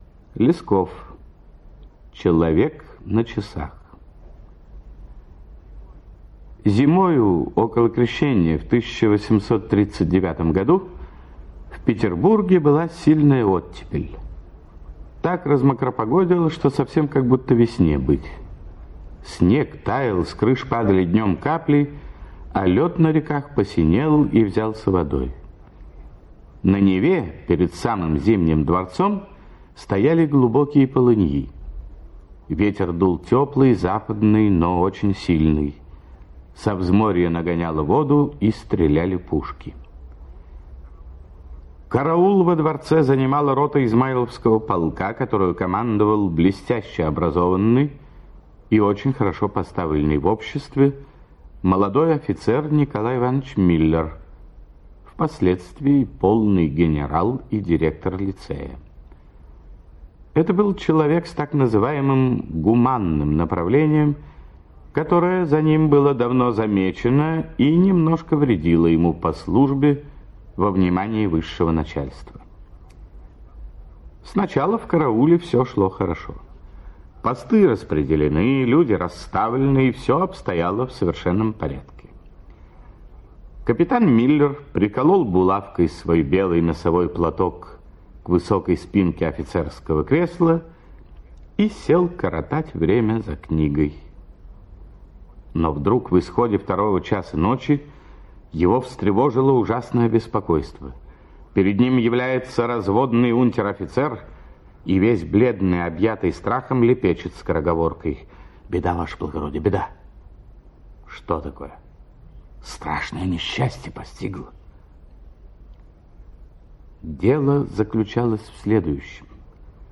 Человек на часах - аудио рассказ Лескова Н.С. Рассказ про рядового Постникова, который покинул свой пост чтобы спасти тонувшего человека.